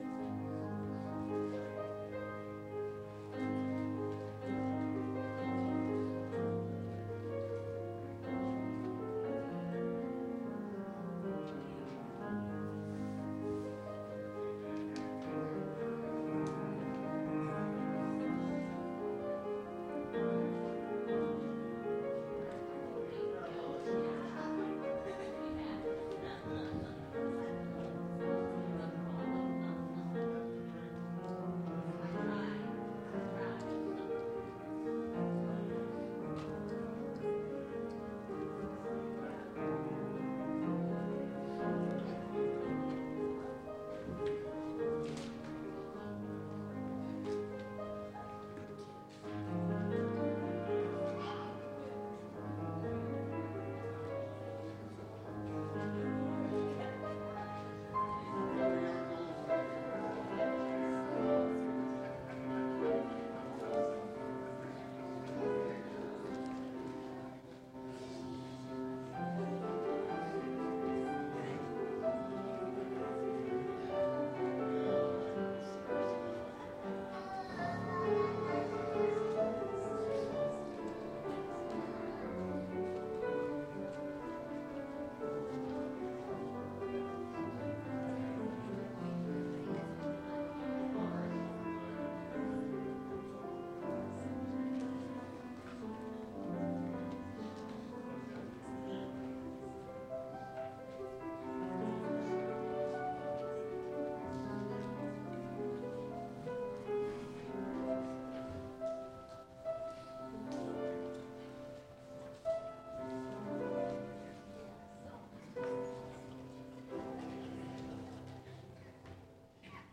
Calvin Christian Reformed Church Sermons
Welcome/Announcements* Call to Worship* Song of Worship